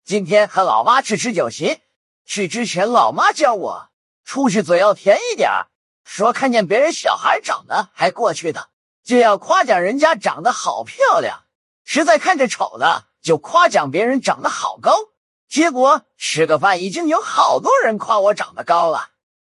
我们现在已经成功训练并获得了一个TTS模型。